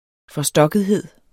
Udtale [ fʌˈsdʌgəðˌheðˀ ]